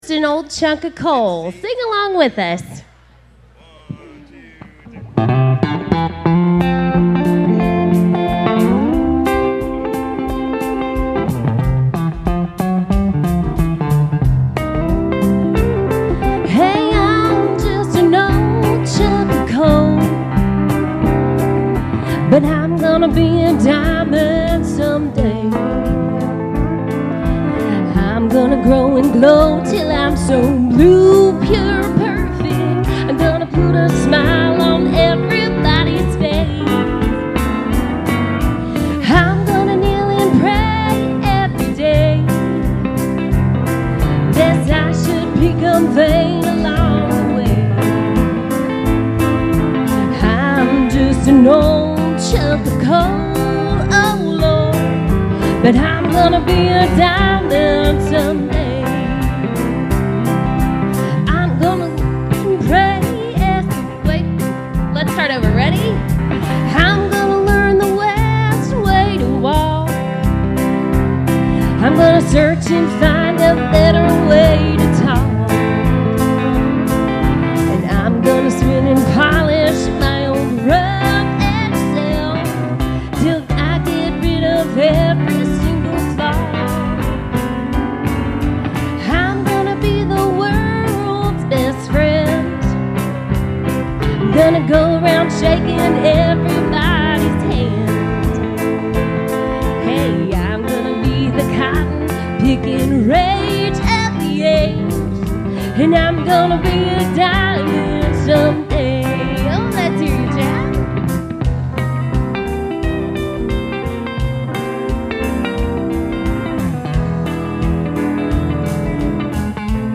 This biblical teaching from Philippians 1 provides practical applications for developing a gospel perspective that finds meaning in hardship.